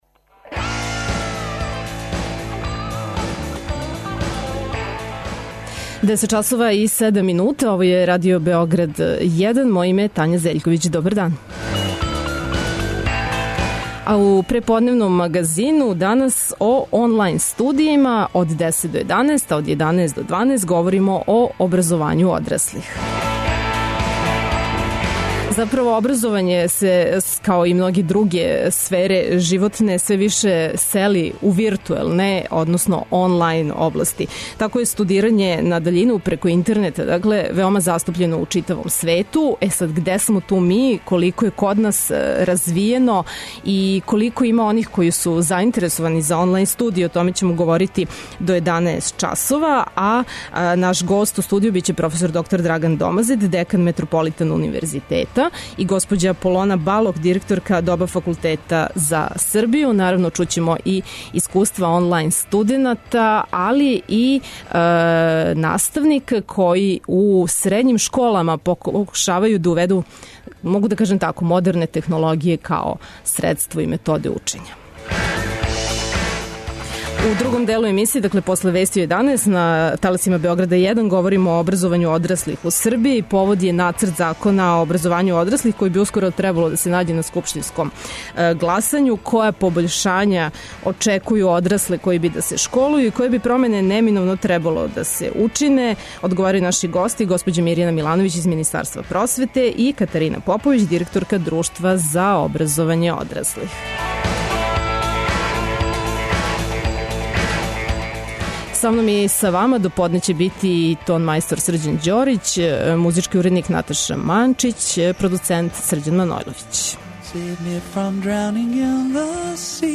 Чућемо и искуства online студената.